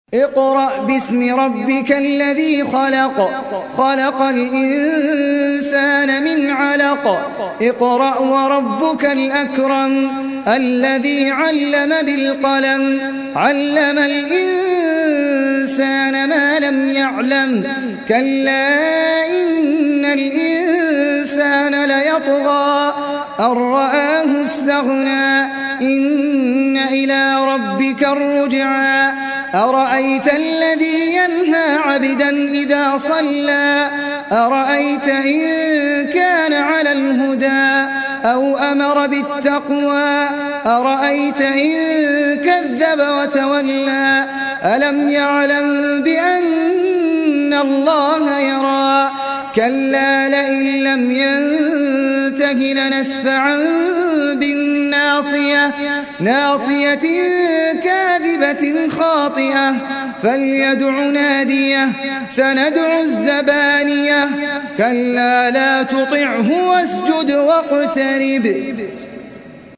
سورة العلق | القارئ أحمد العجمي